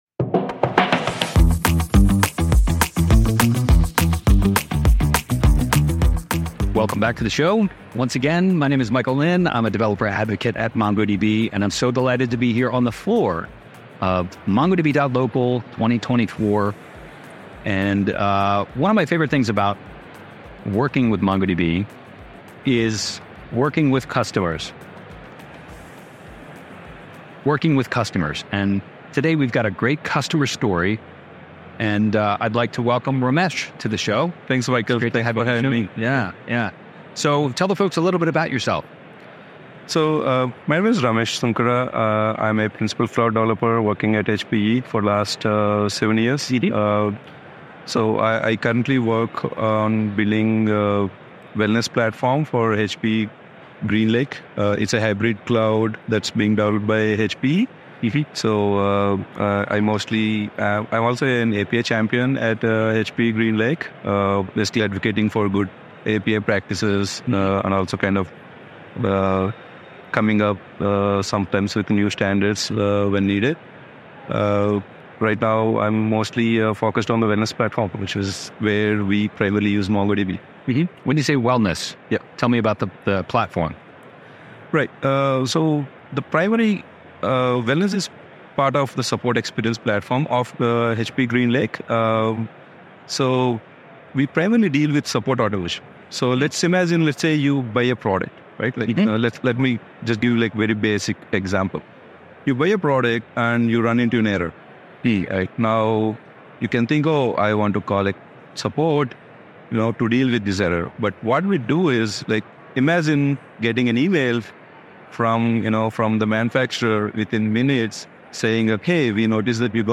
In this episode of MongoDB Global 2024, we chat